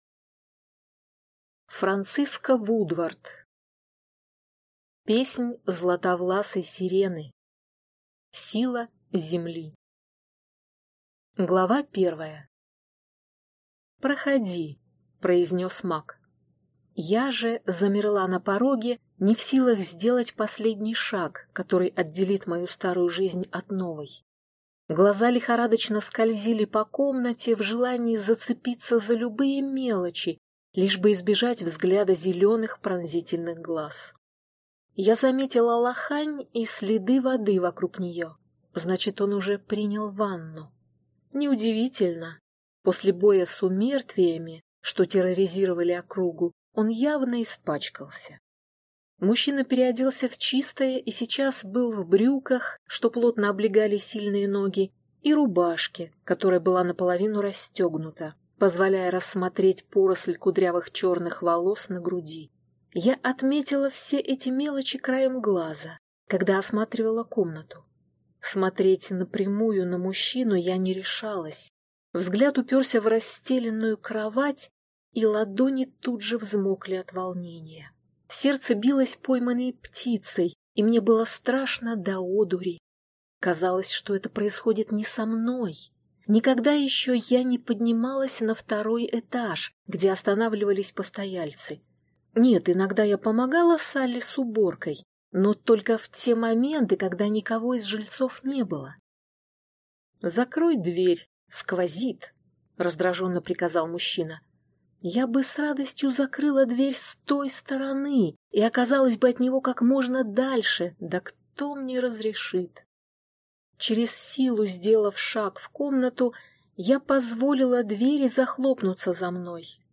Аудиокнига Песнь златовласой сирены. Сила Земли | Библиотека аудиокниг